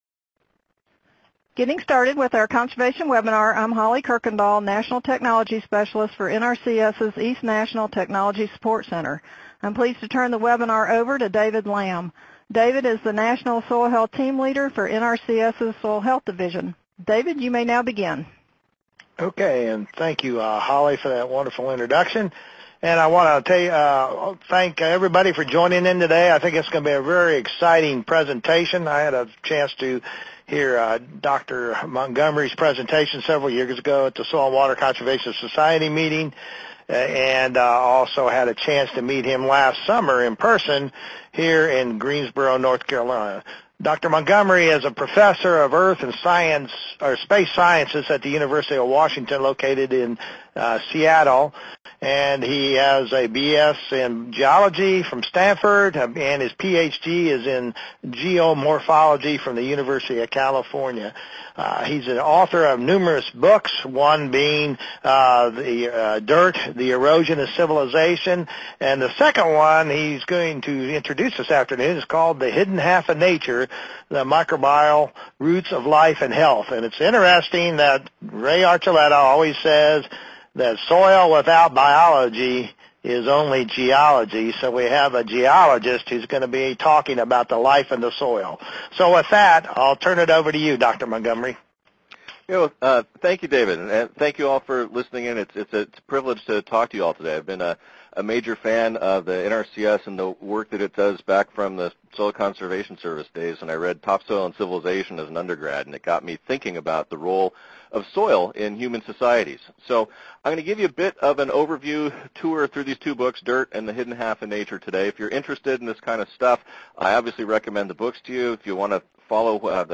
Presenter(s): David R. Montgomery , Ph.D., Professor, Quaternary Research Center and Department of Earth and Space Sciences, University of Washington, Seattle, WA
Streaming Podcast Webinar Audio - CEUs and certificates are not available for podcasts.